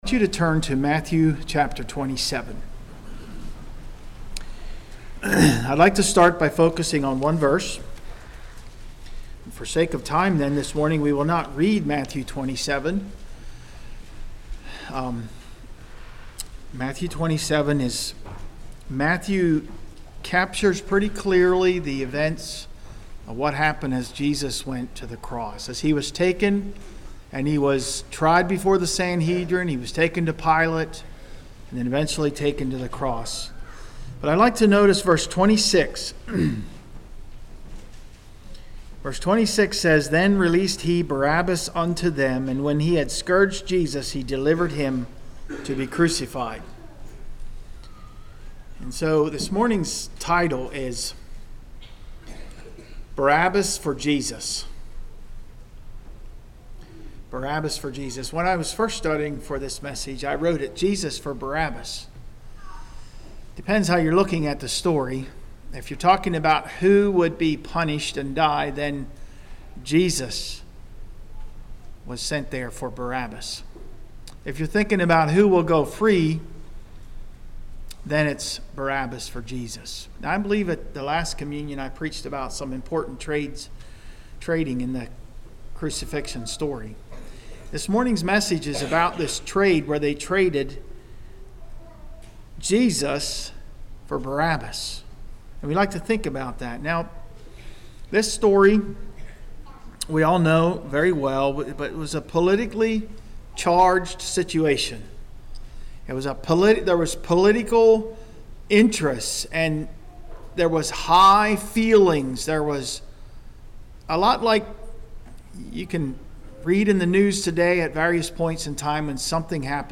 Congregation: Blue Ridge